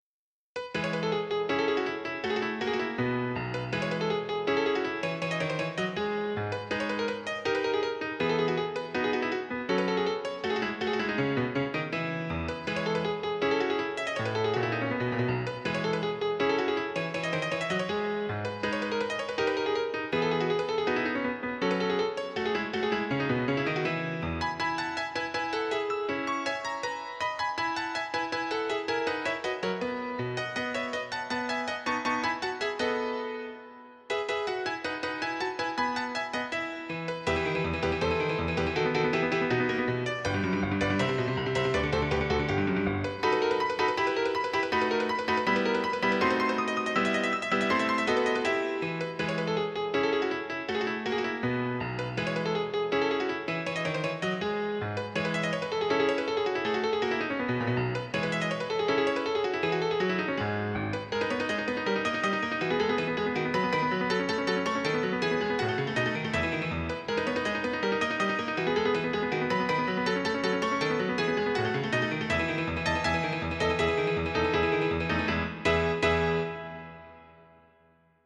música clásica
sonata